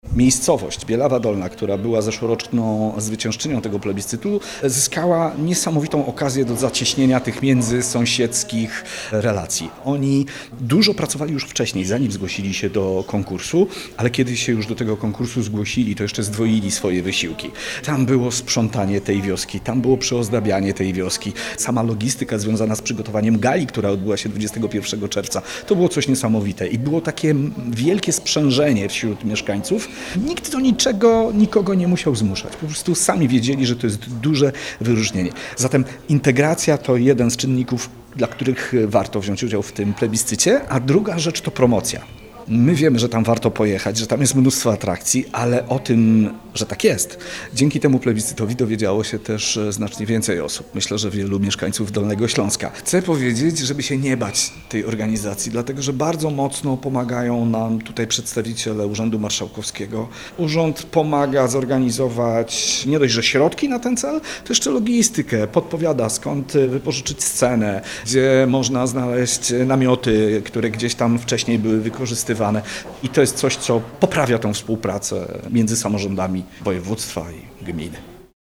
Mieszkańcy pokazali piękno wsi – także w kontekście relacji ludzkich – co dziś skutkuje zwiększonym ruchem turystycznym w tej przygranicznej miejscowości, zaznacza Janusz Pawul, burmistrz Gminy Pieńsk.
04_wojt-gminy-Piensk_Bielawa-Dolna.mp3